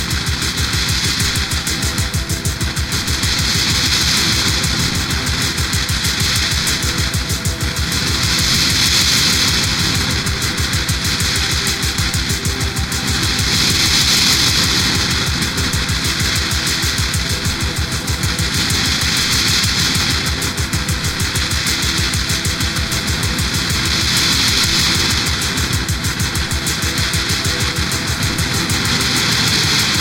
O shoegaze é o alucinógeno mais regular